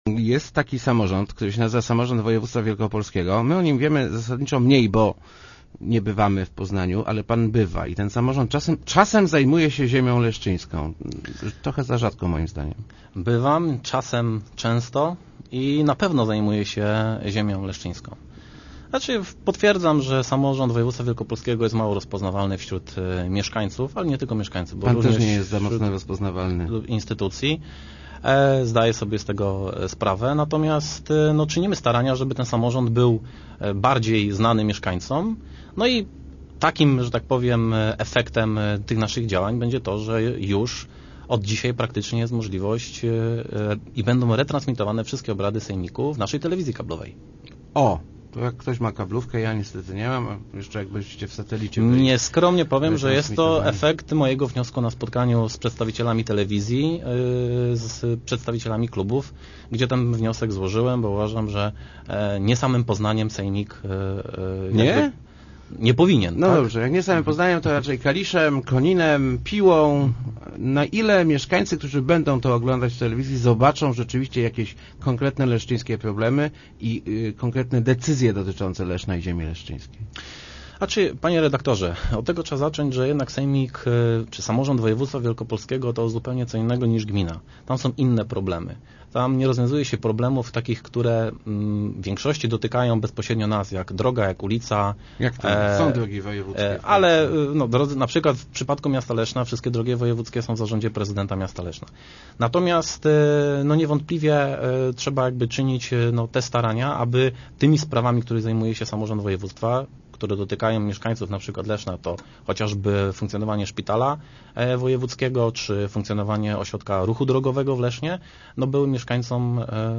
wisniewski.jpgWojewódzki Ośrodek Ruchu Drogowego w Lesznie zajmuje pierwsze miejsce w Wielkopolsce w statystyce oblanych egzaminów na prawo jazdy – mówił w Rozmowach Elki Maciej Wiśniewski, radny Sejmiku Województwa Wielkopolskiego.